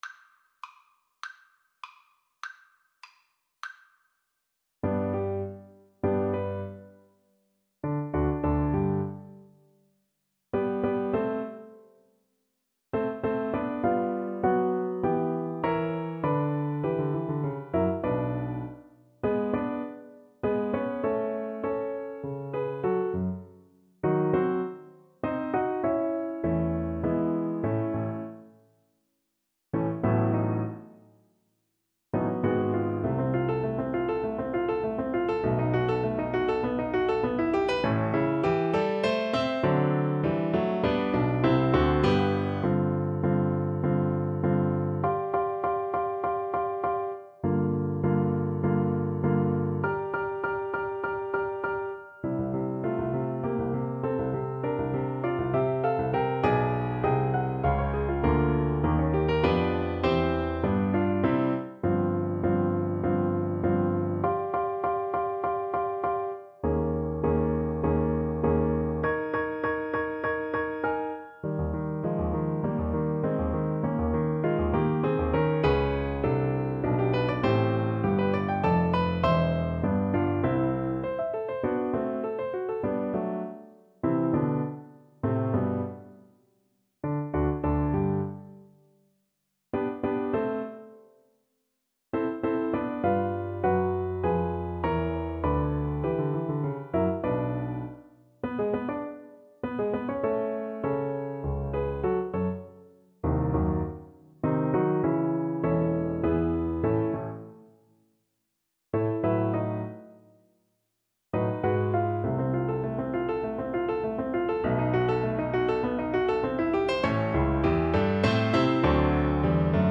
Play (or use space bar on your keyboard) Pause Music Playalong - Piano Accompaniment Playalong Band Accompaniment not yet available reset tempo print settings full screen
G major (Sounding Pitch) (View more G major Music for Violin )
~ = 200 Allegro Animato (View more music marked Allegro)